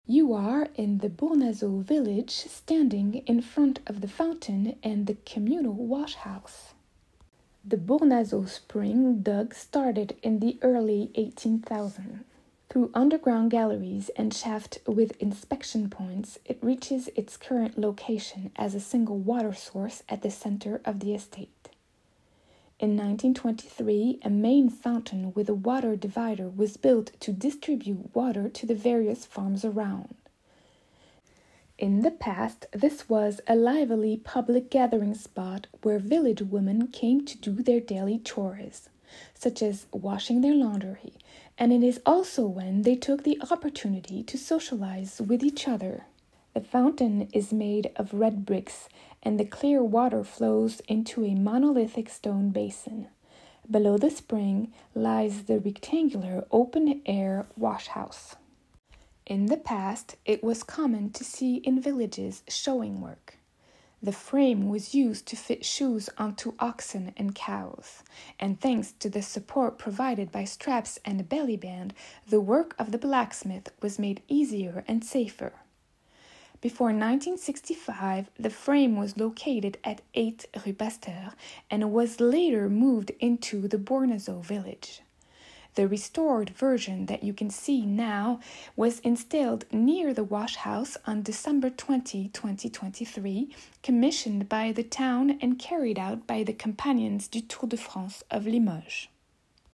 Bournazeau_version-anglaise.mp3